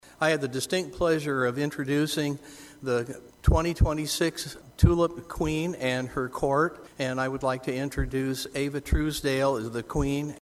REPRESENTATIVE. TOM JENEARY OF LE MARS INTRODUCED THEM: